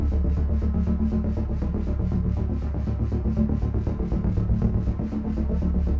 Index of /musicradar/dystopian-drone-samples/Tempo Loops/120bpm
DD_TempoDroneE_120-D.wav